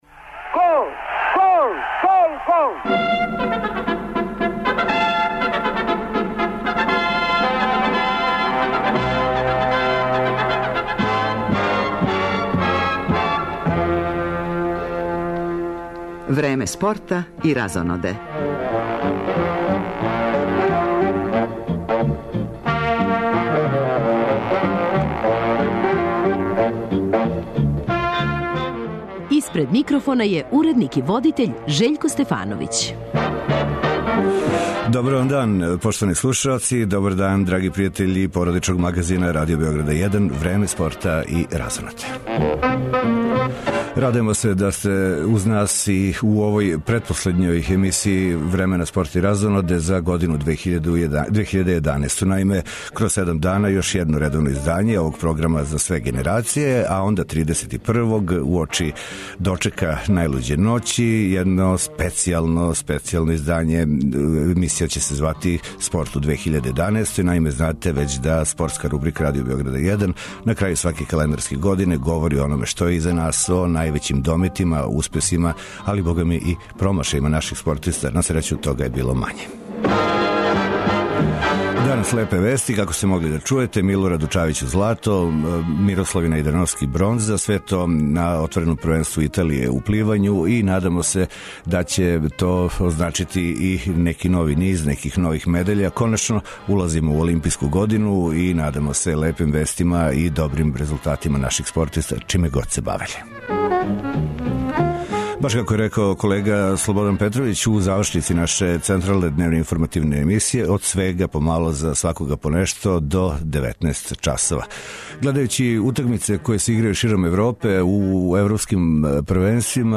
Ту је и уобичајени преглед првенственог одбојкашког кола, уз изјаве актера утакмица.